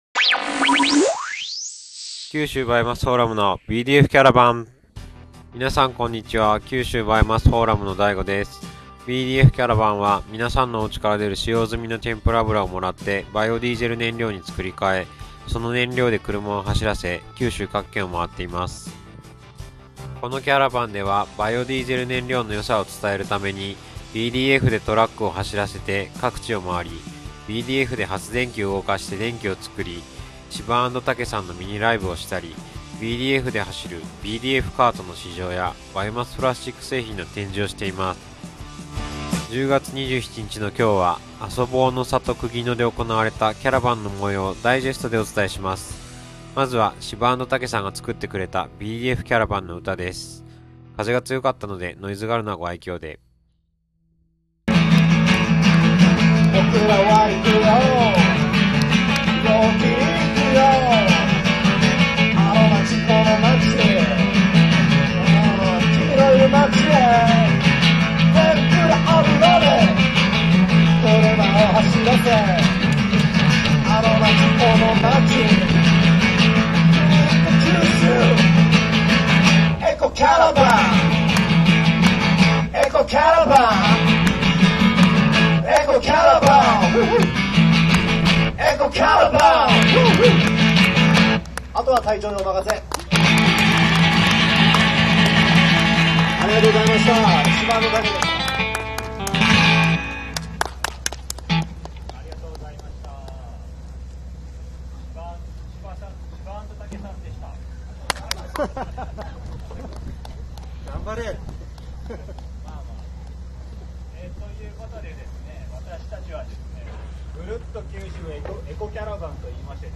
今日のBDFキャラバンは南阿蘇のあそ望の里くぎのへ行きました。